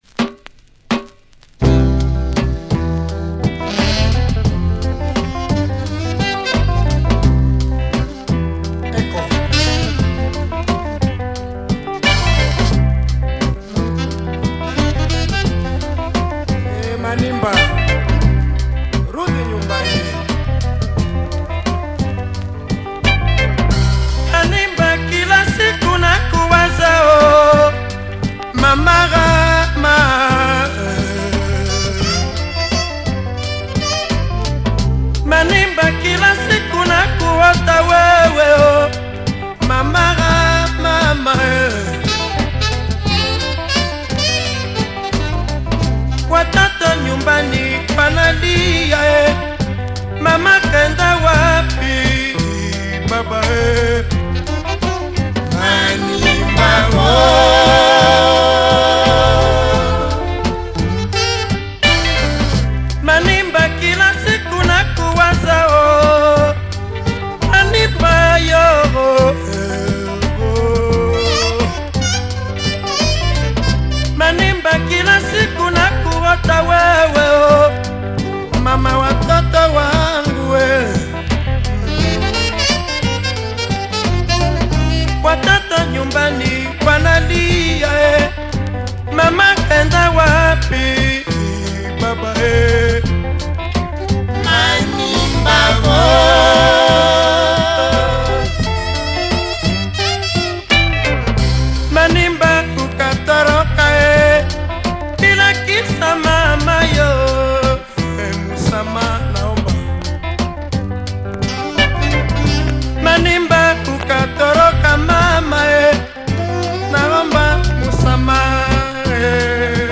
Great mellow Swahili grover in Rumba mode